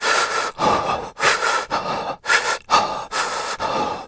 Male Vocal Reactions » Breathless Coughing 2
描述：Breathless Coughing: a young adult male coughing and panting weakly after being choked. Recorded using my Turtlebeach EarforceX12 headset and then edited in Audacity to sound more like a man.
标签： man cough breathless human breathing vocal male coughing choking voice
声道立体声